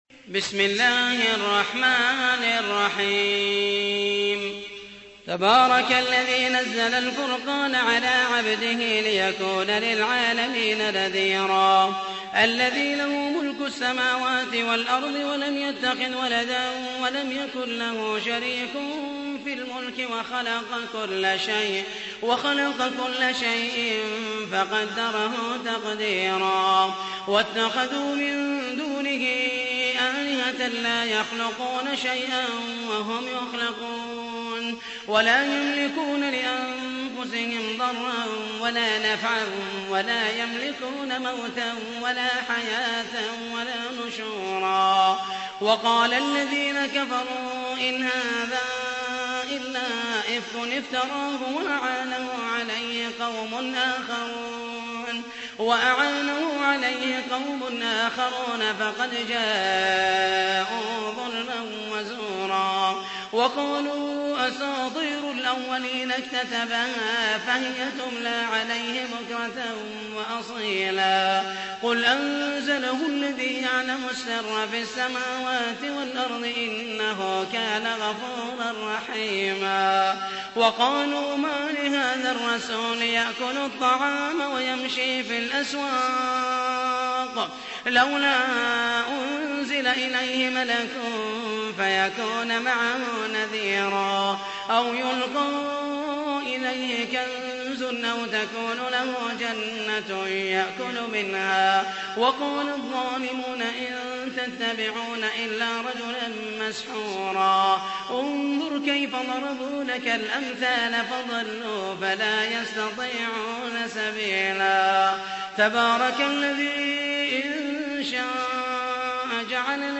تحميل : 25. سورة الفرقان / القارئ محمد المحيسني / القرآن الكريم / موقع يا حسين